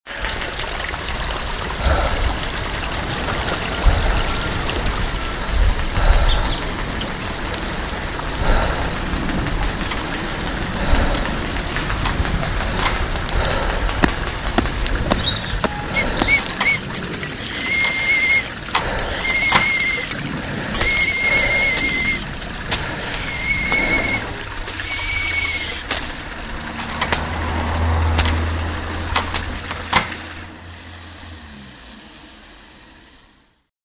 Aus vielen einzelnen Geräuschen haben die Jugendlichen der Our Voice-Redaktion rhythmische Collagen geschnitten.
Auf der Jagd nach Geräuschen pirschten sich die Jugendlichen mit ihren Aufnahmegeräten in den nahen Wald und zeigten sich überaus kreativ, was die selbständige Produktion von Geräuschen angeht.
Die einen schlagen verschiedene Stöcke gegen Bäume und bekommen jeweils deutlich unterscheidbare Schlaggeräusche. Andere erzeugen eine Vielzahl an Schritt-, Schlurf-, oder Stampfgeräusche, je nach Untergrund und Mikrofonposition variieren die Geräusche erheblich.
Ein Auto fährt vorbei, der Bach plätschert - aufnehmen und wahrnehmen, was uns im Alltag kaum auffällt. Und dann selbst aktiv werden: ein geworfener Stein prallt auf ein Straßenschild liefert ein überraschendes Dong, der über den Steinboden gezogene Sessel klingt wie ein brüllender Löwe.